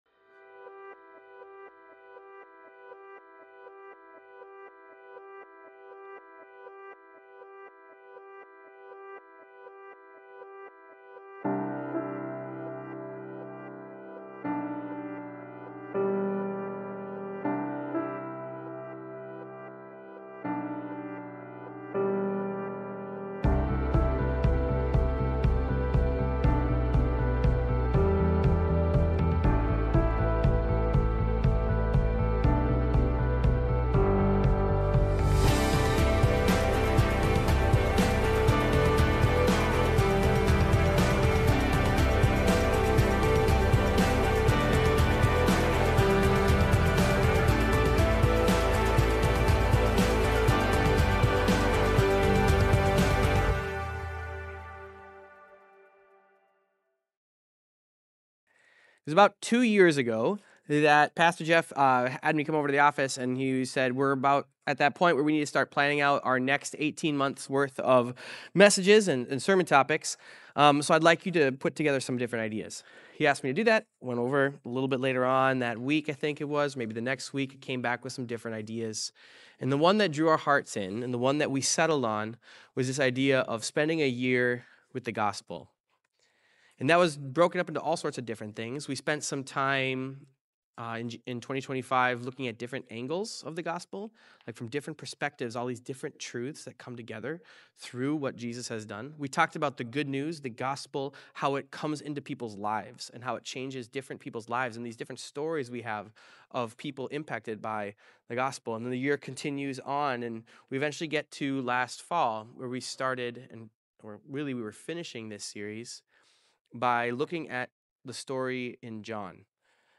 Fulfillment-Sermon-3.1.26.m4a